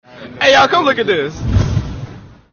funny comedy Sound Effect No Copyright RMtv